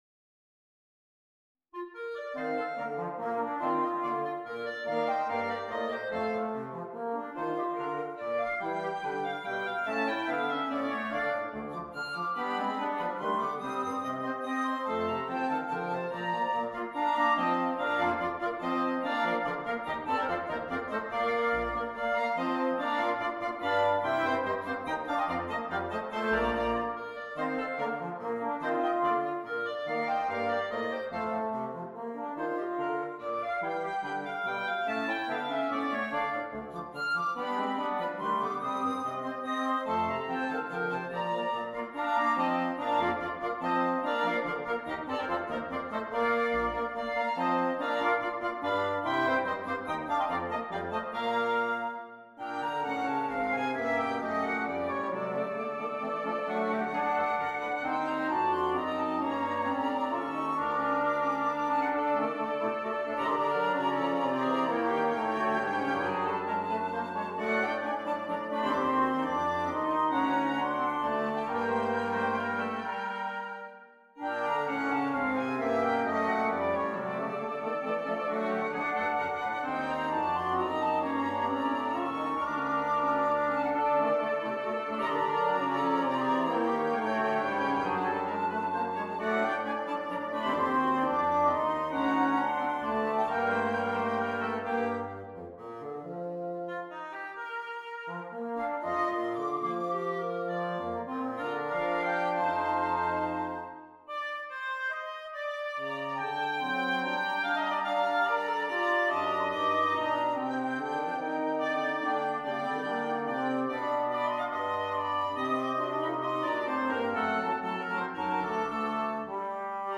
Woodwind Quintet